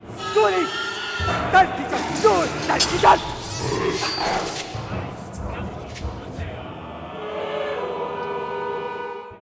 [from non-commercial, live recordings]